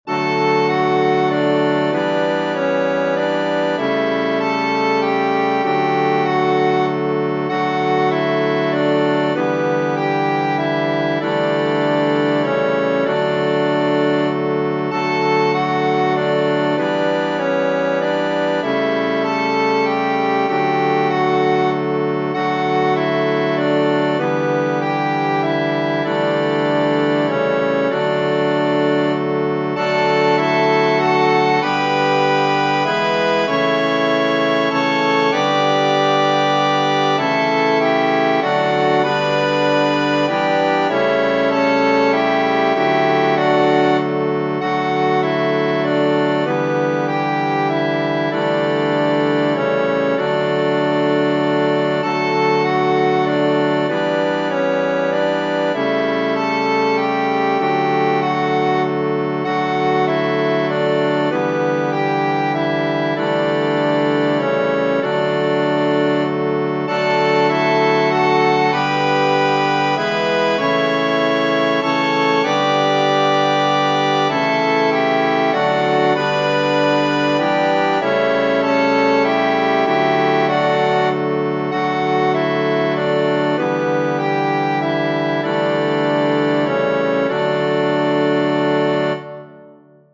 Tradizionale Genere: Religiose Testo di E. M. Caglio, melodia tradizionale.